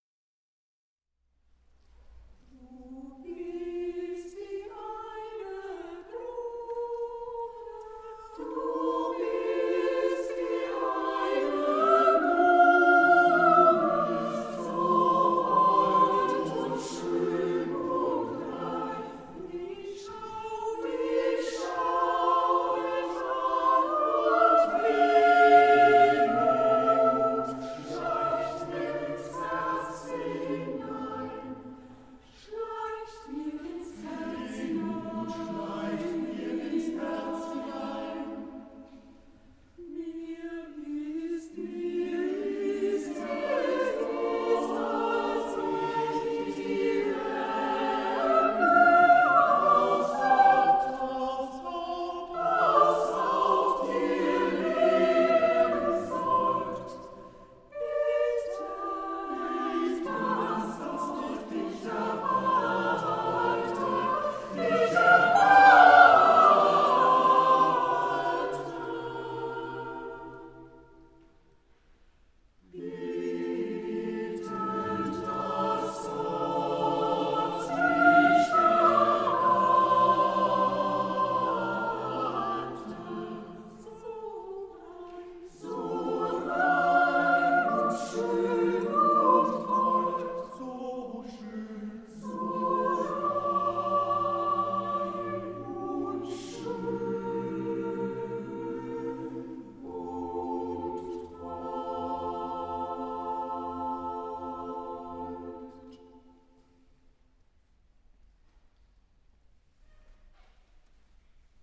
Hörbeispiele – Venti Voci Kammerchor Braunschweig
Anton Bruckner: Du bist wie eine Blume, Livemitschnitt vom 29.03.25 in St. Magni, Braunschweig